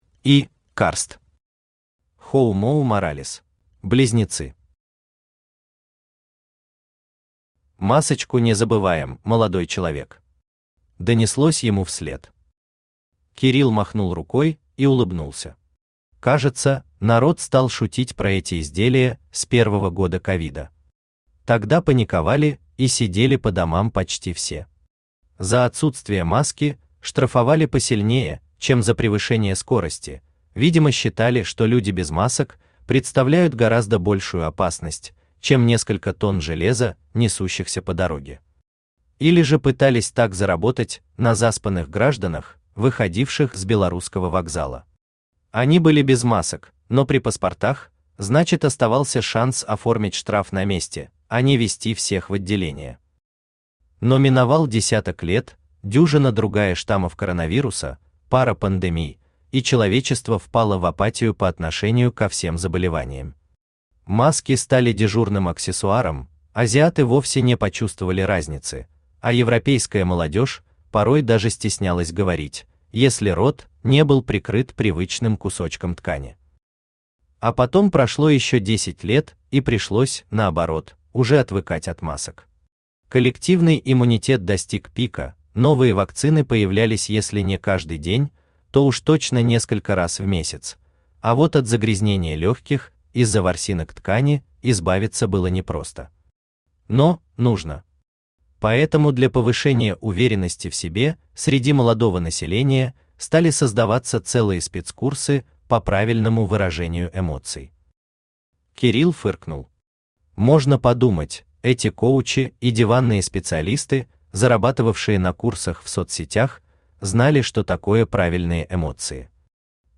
Аудиокнига Homo moralis | Библиотека аудиокниг
Aудиокнига Homo moralis Автор И. Карст Читает аудиокнигу Авточтец ЛитРес.